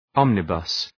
Shkrimi fonetik {‘ɒmnə,bʌs}
omnibus.mp3